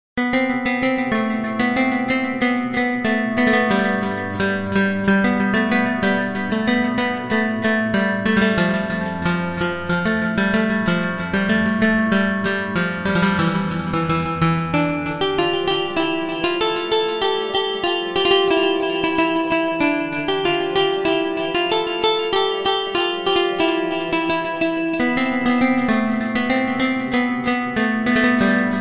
Category: Islamic Ringtones